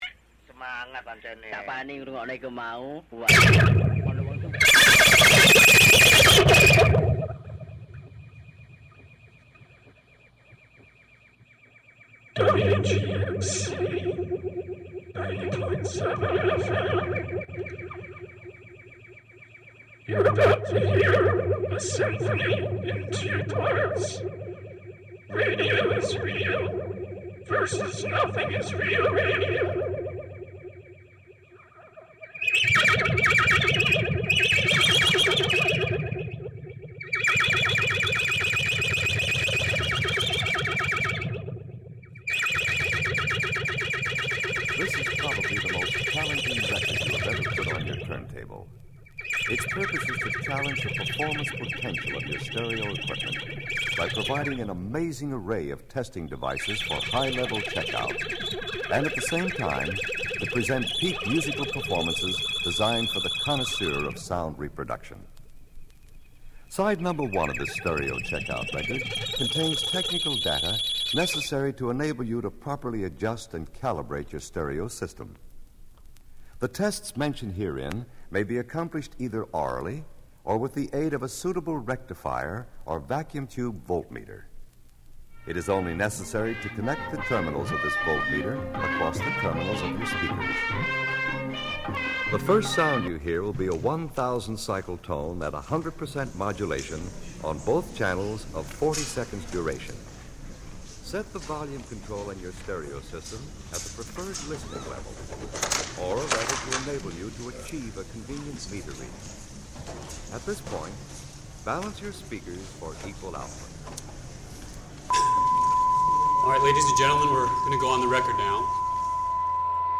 Live in-studio performance
utilizing found, prerecorded and live sounds
The first "movement" would be darker and less hopeful, representing a world without freedom and without community radio. The second "movement" would be more joyful and playful, representing the world with community radio and all of its freedoms of expression, symbolizing the positive changes WGXC and free103point9 are making for our area and the airwaves already. For the first "movement" I made several pre-recorded cassette tape loops using obnoxious TV ads, radio static, industrial noises, public hearings, and other bleak sources so as to convey darkness and despair.